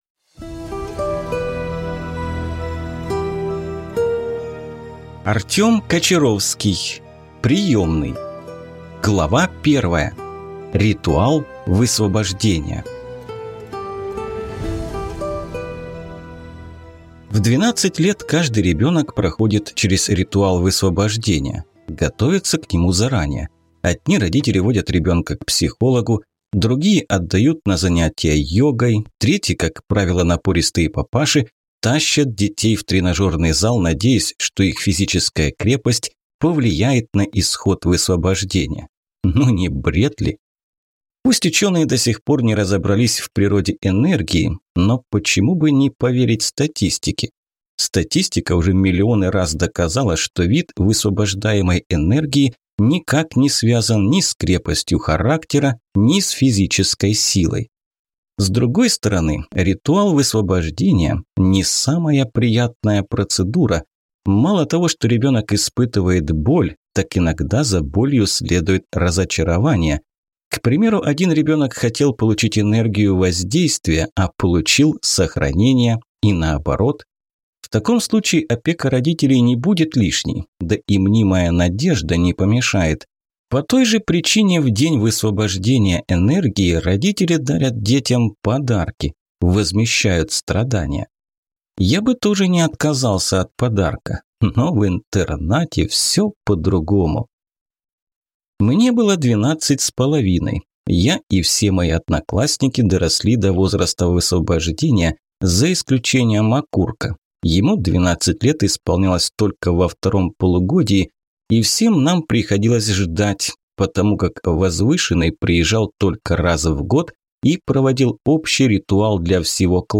Аудиокнига Приемный | Библиотека аудиокниг